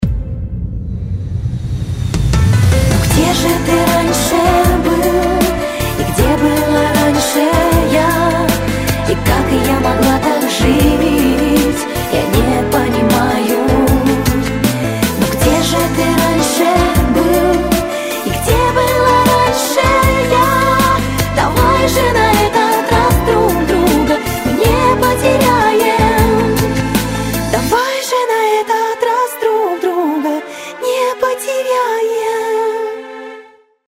с красивым женским голосом
Нежные рингтоны
Поп